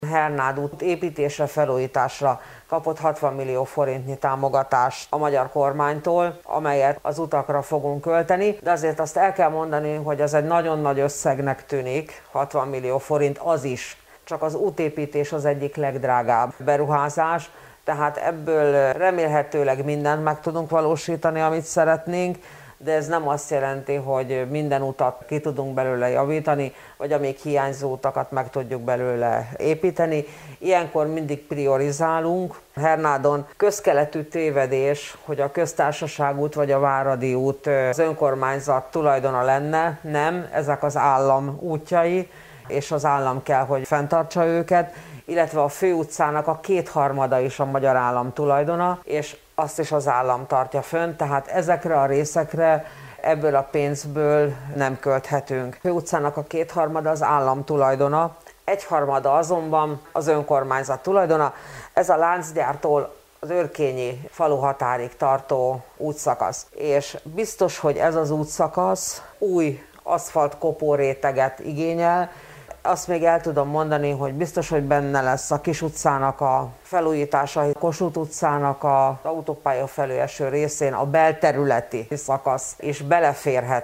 Hajnal Csilla polgármestert hallják: